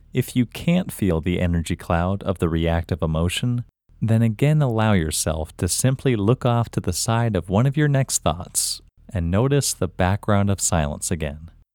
LOCATE OUT English Male 31
Locate-OUT-Male-31.mp3